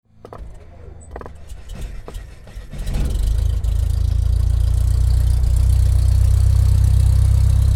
Звук завода пропеллера самолета
Шум винта самолета при запуске
Пропеллер аэроплана заводится с гулом